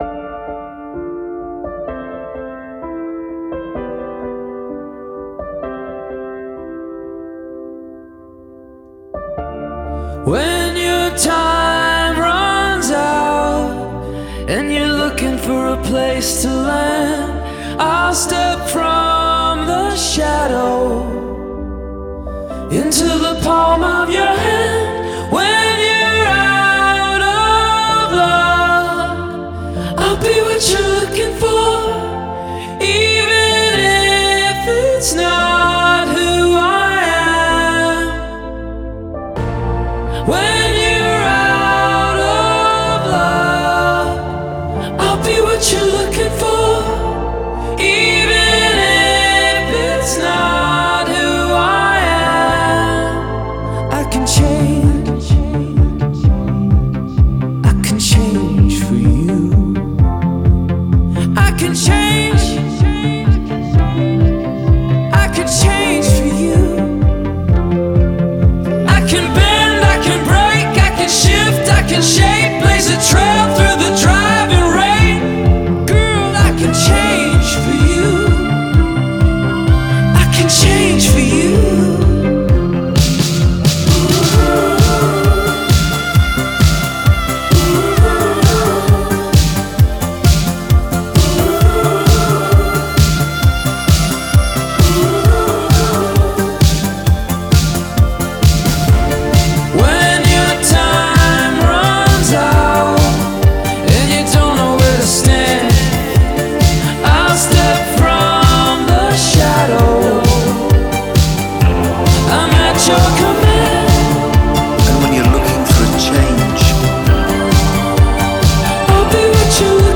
Genre: Pop Rock, Indie Rock